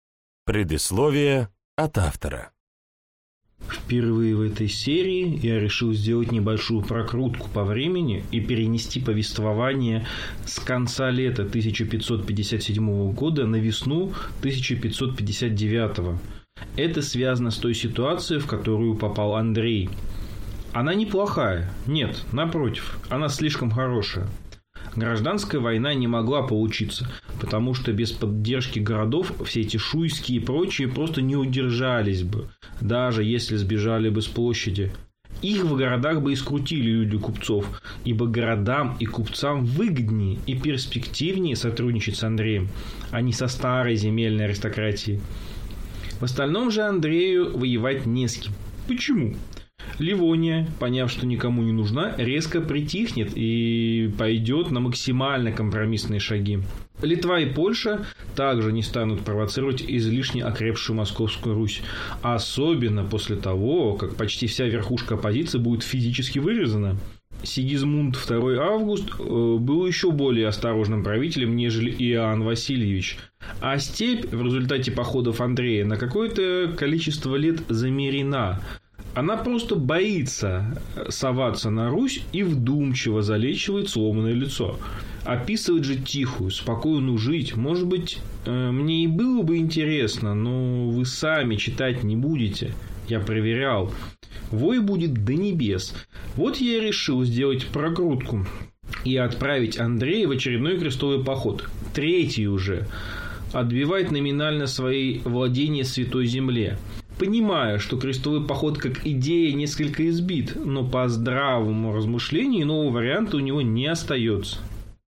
Аудиокнига «Секретная часть».